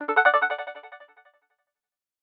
HTC Bildirim Sesleri
Ses çözünürlüğü 192 Kbps / 16 bits ve ses örnekleme oranı 48 Khz olarak üretilen sesler stereo ve yüksek ses çıkışına sahiptir.